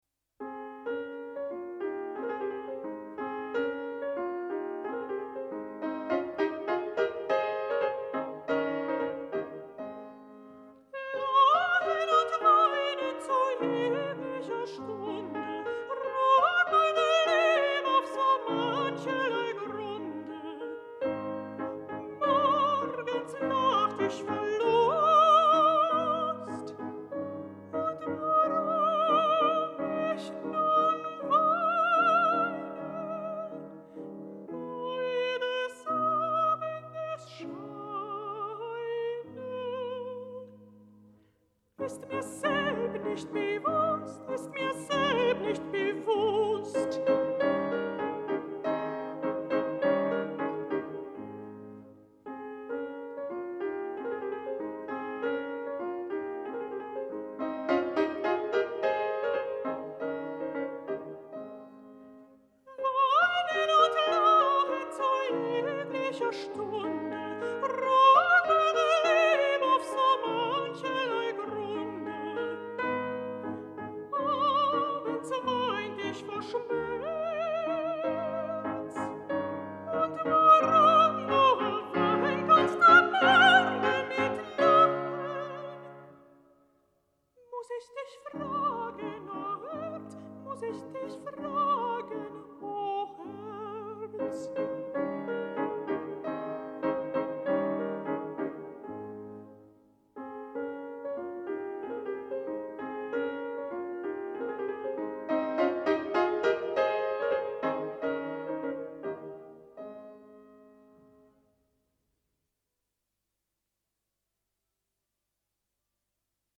Lieder
Boy Soprano
Piano